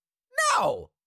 Cartoon Little Monster, Voice, No Sound Effect Download | Gfx Sounds
Cartoon-little-monster-voice-no.mp3